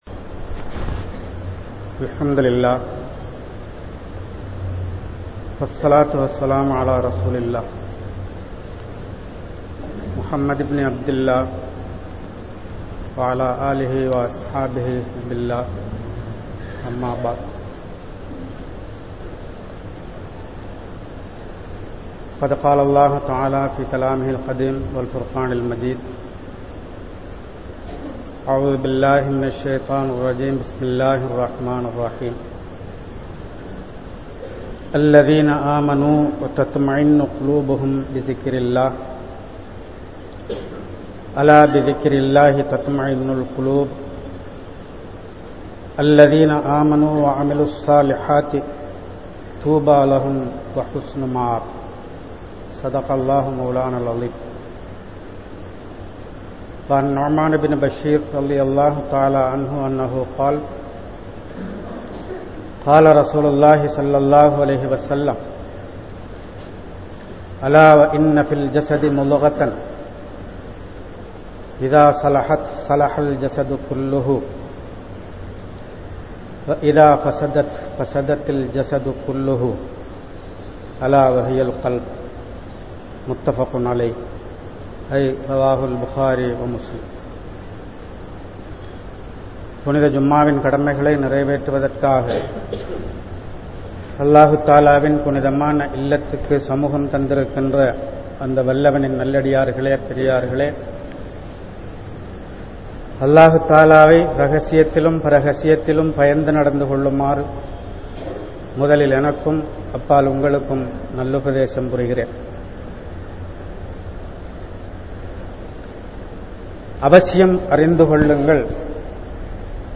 Ullangalai Seer Paduthugal (உள்ளங்களை சீர்படுத்துங்கள்) | Audio Bayans | All Ceylon Muslim Youth Community | Addalaichenai
Grand Jumua Masjith